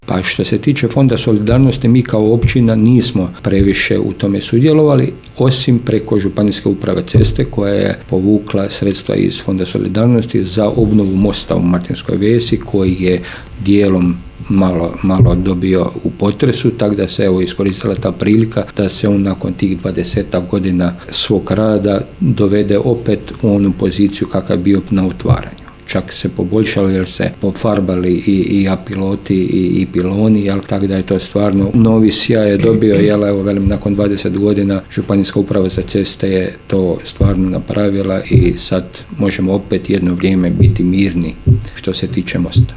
Više o tome, načelnik Stjepan Ivoš